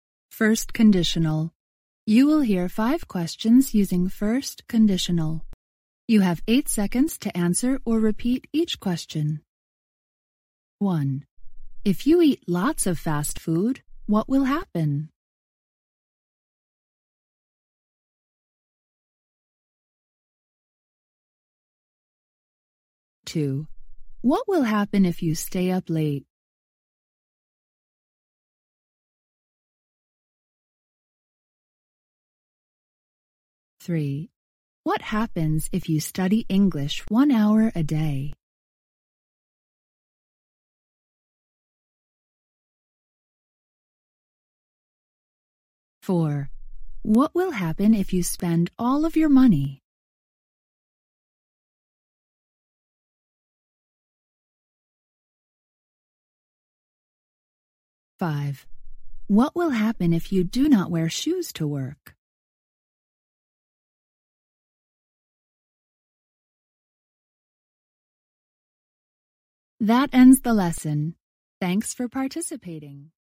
You will hear five questions using First Conditional.
You have eight seconds to answer or repeat each question.